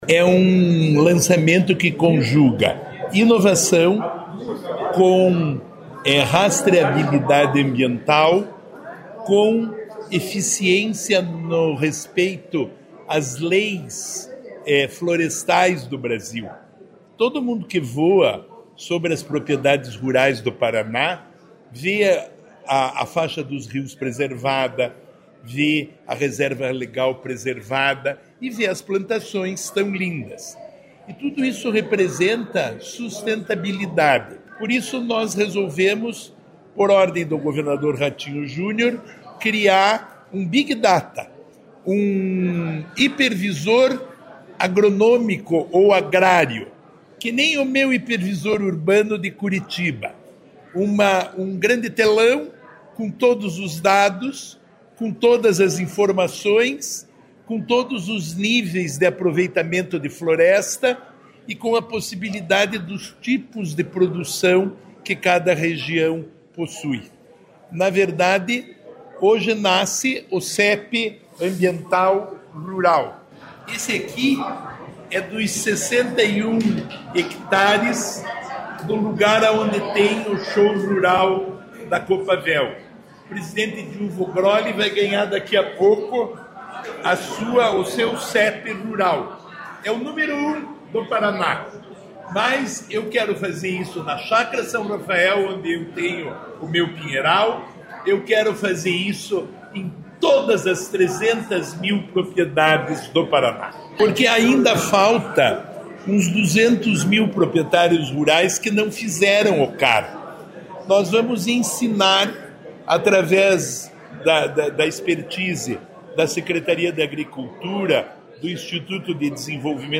Sonora do secretário Estadual do Desenvolvimento Sustentável, Rafael Greca, sobre os anúncios relacionados à sustentabilidade no campo no Show Rural